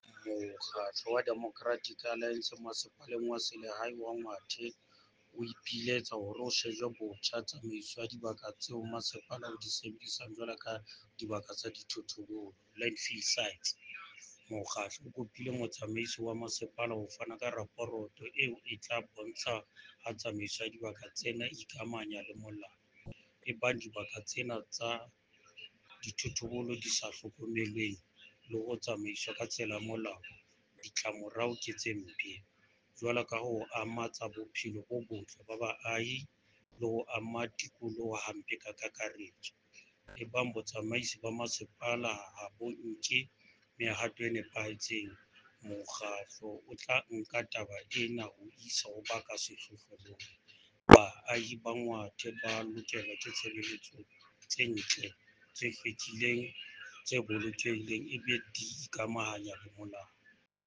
Sesotho soundbites by Cllr Small Sehume.